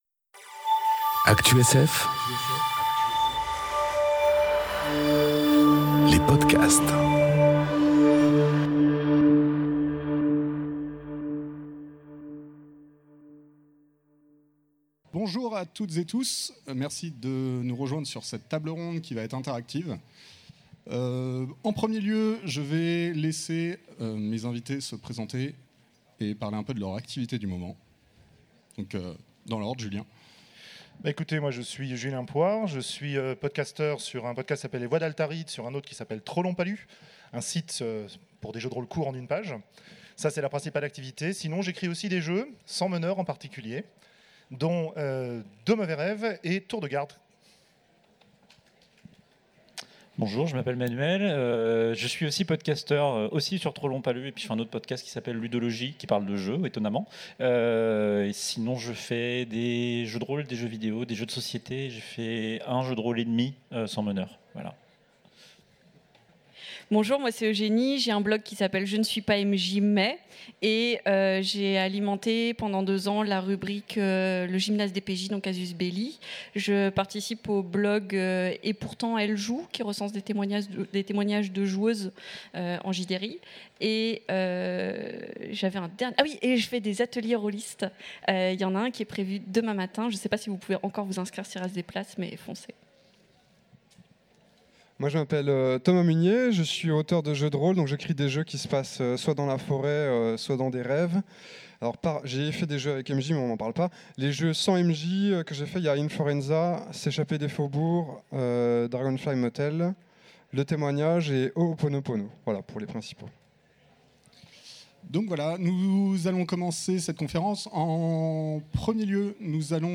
Conférence Jouer sans meneur enregistrée aux Utopiales 2018